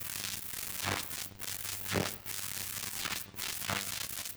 SFX_Static_Electricity_Short_01.wav